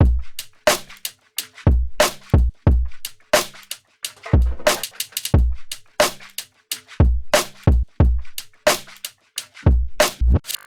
drums2